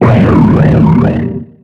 Cri de Tutankafer dans Pokémon X et Y.